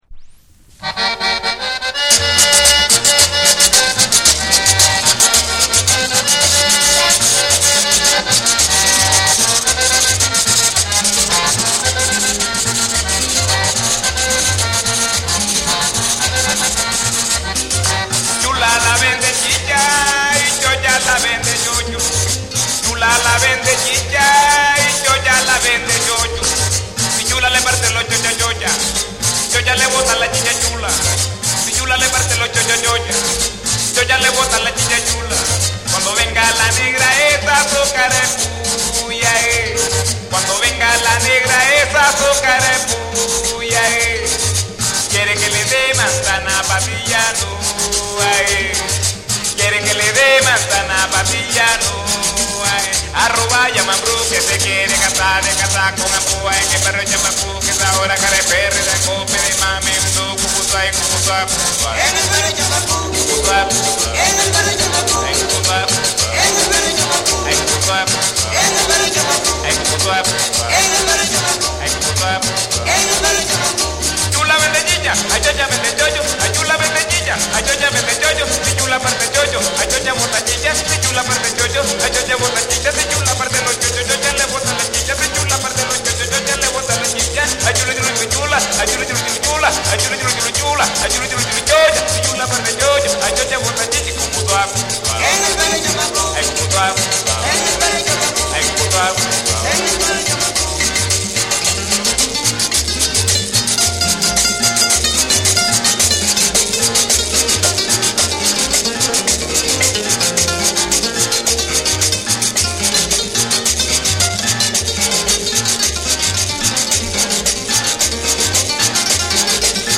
WORLD